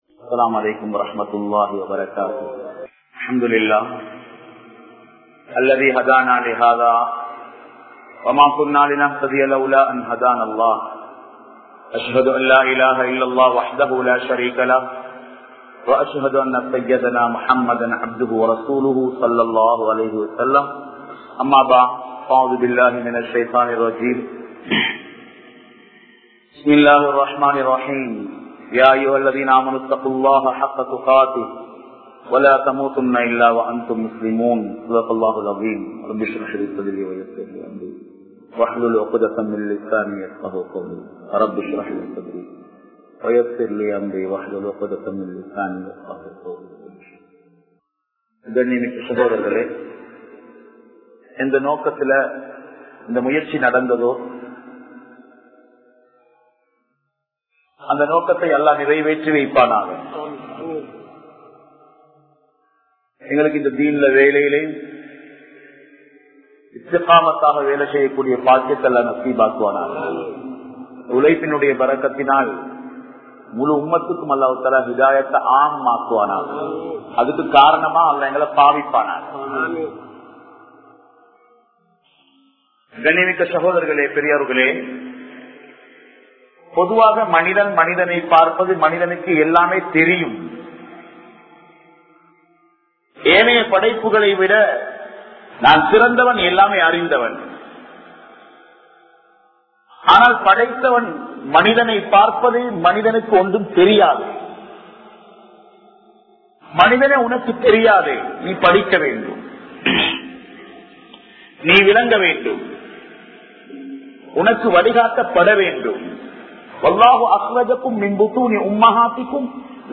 Oru Mumeenin Panpu Eppadippattathu? (ஒரு முஃமீனின் பண்பு எப்படிப்பட்டது?) | Audio Bayans | All Ceylon Muslim Youth Community | Addalaichenai
Mawanella, Danagama, Masjidhul Hakam Jumua Masjidh